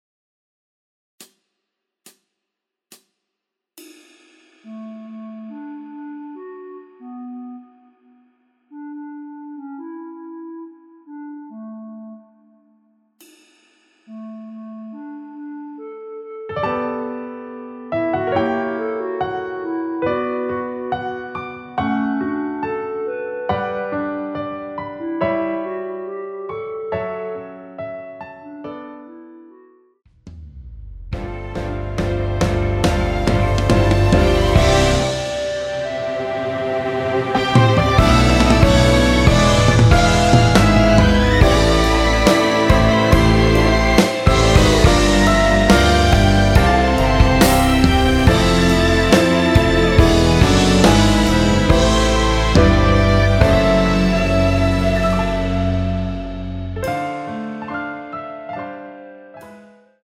원키에서(-8)내린 멜로디 포함된 MR입니다.
앞부분30초, 뒷부분30초씩 편집해서 올려 드리고 있습니다.